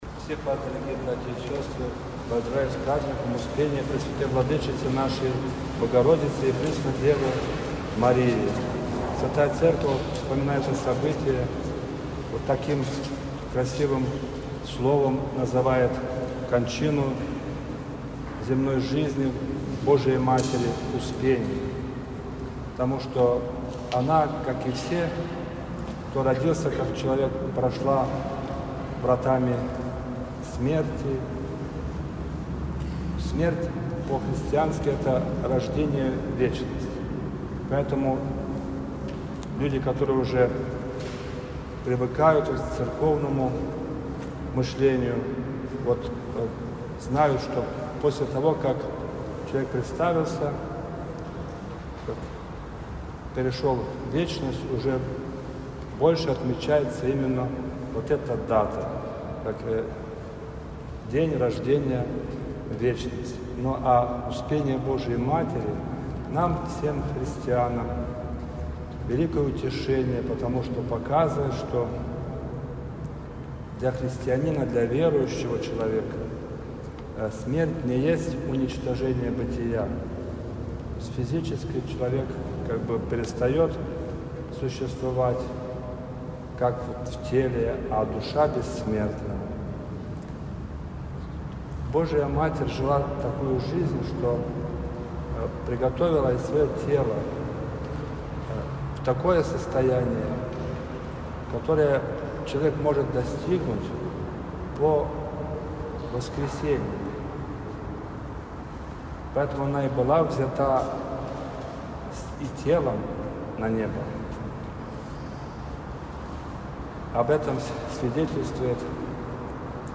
Всенощное бдение с чтением акафиста
Проповедь
Всенощное-бдение.mp3